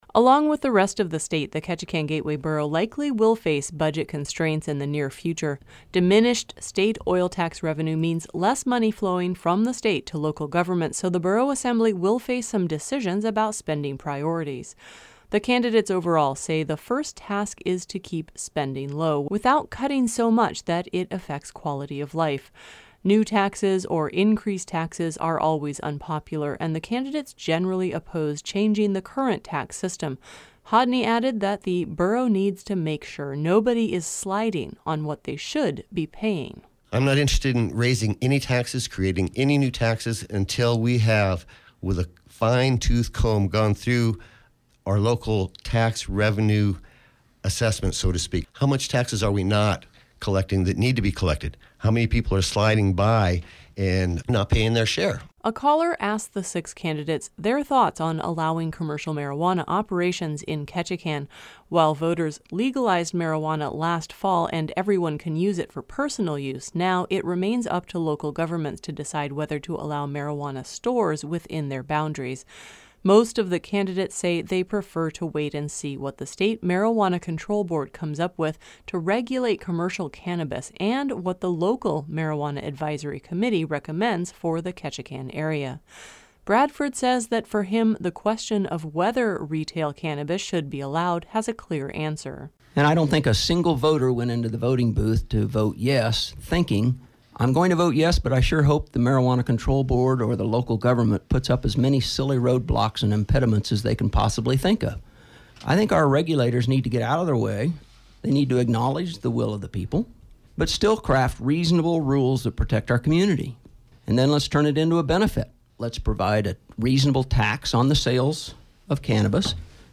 A caller asked the six candidates their thoughts on allowing commercial marijuana operations in Ketchikan.
Another caller asked about consolidation of the borough and city governments.